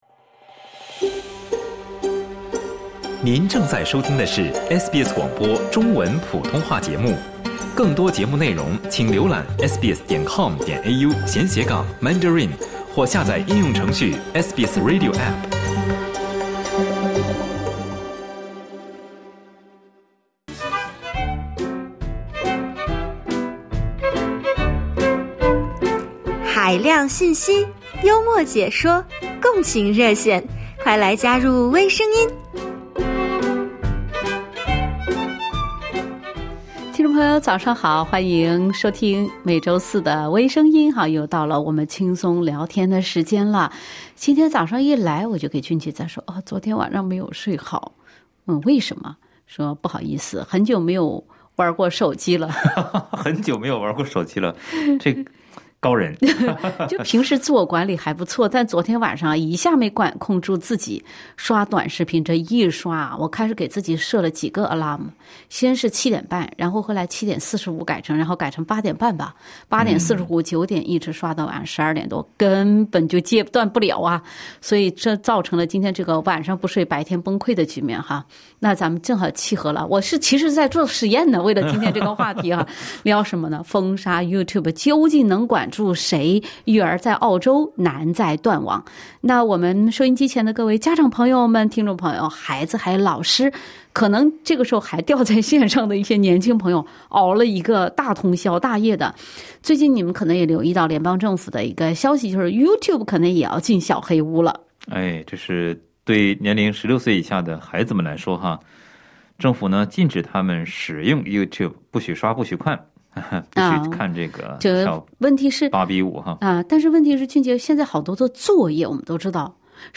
家长自己如果都做不到“以德服人”，怎么管理孩子使用电子设备？热心听众分享个人经验。